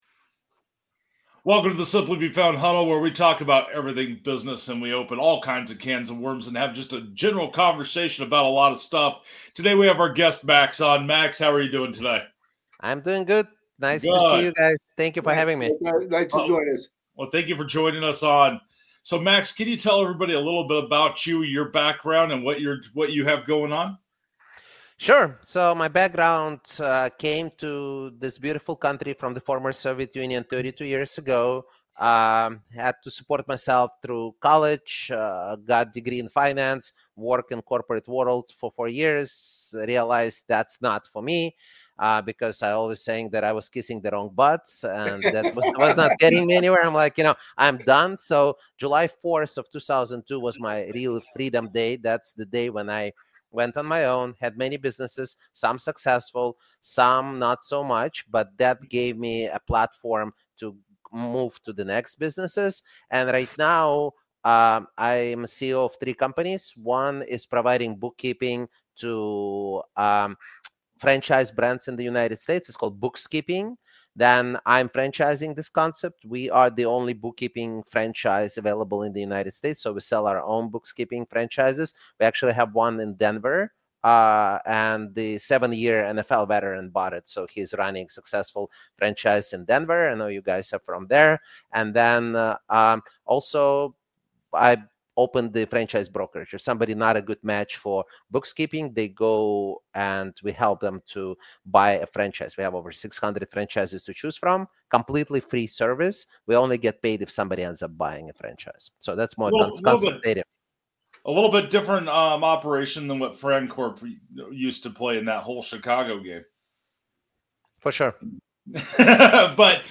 From stories of NFL veterans launching successful franchises to real talk about why not all businesses are fit for franchising—this is one conversation you don’t want to miss!